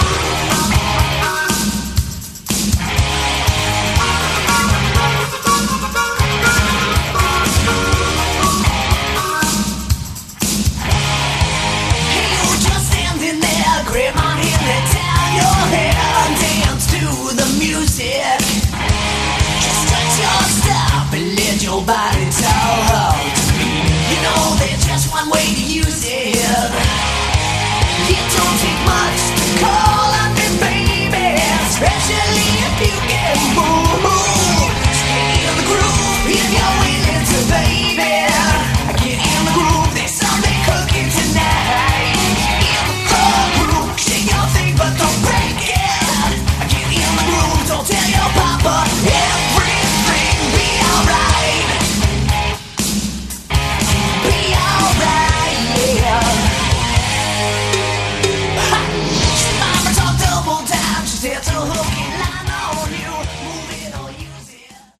Category: Glam
vocals
guitars
bass
drums